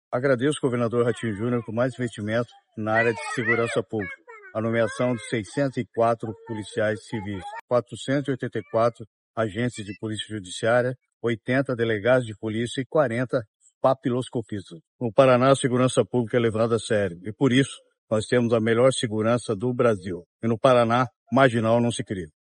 Sonora do secretário da Segurança Pública, Hudson Teixeira, sobre a nomeação de 604 policiais civis